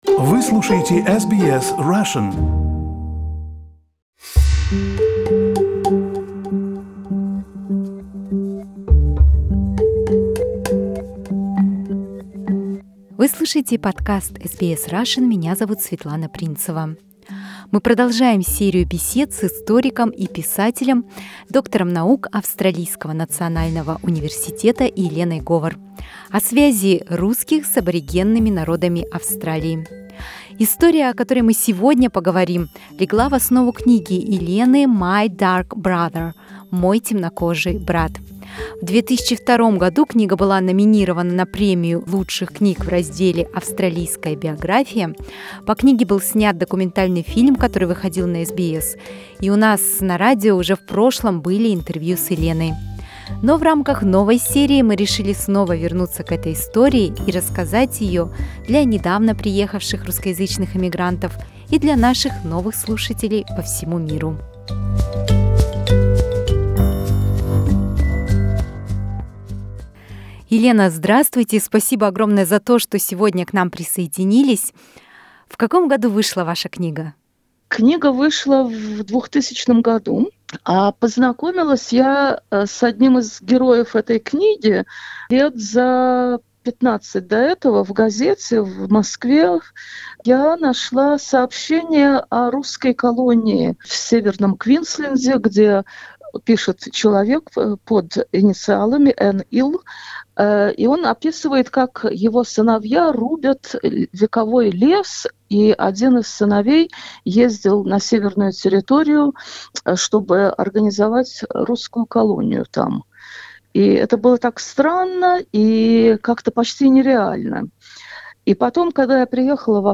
Это интервью записано в студии SBS, которая находится на земле народа Gadigal.